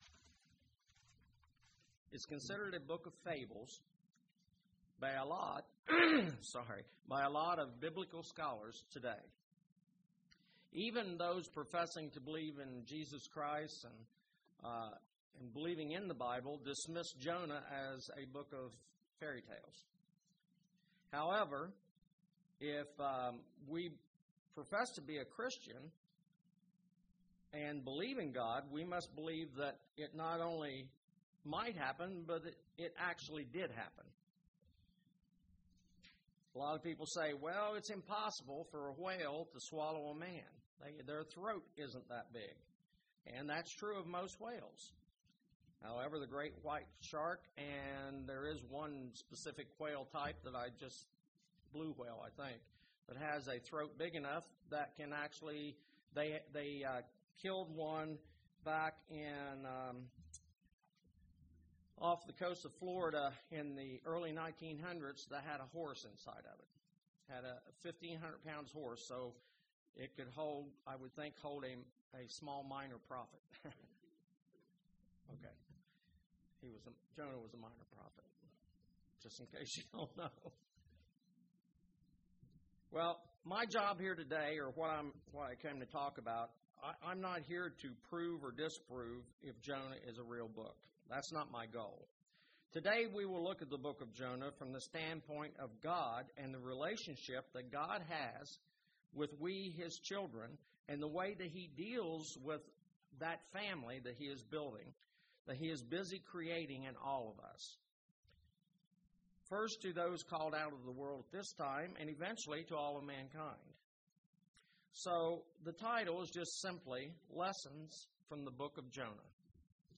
This sermon delves into the book of Jonah and descibes Jonah's reluctance to journey to the corrupt city of Ninevah. Why God decided to give the city another chance.